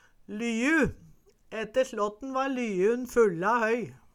Høyr på uttala Ordklasse: Substantiv hokjønn Kategori: Bygning og innreiing Attende til søk